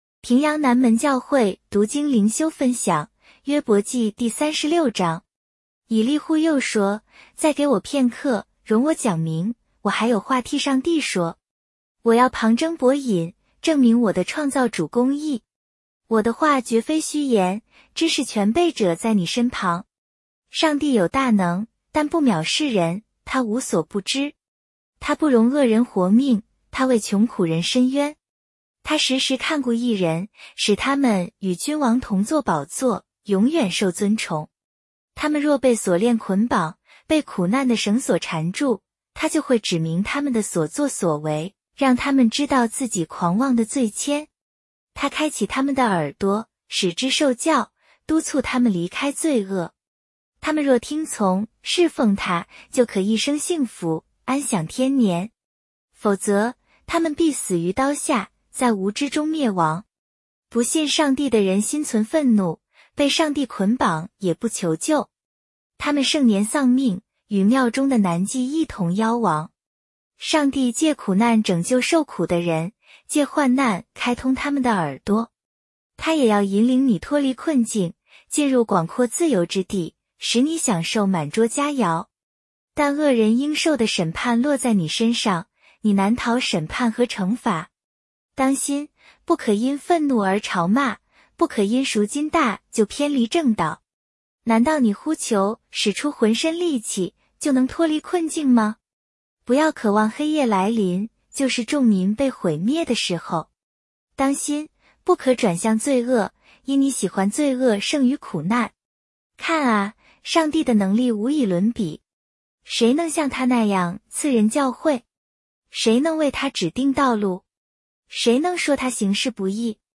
普通话朗读——伯36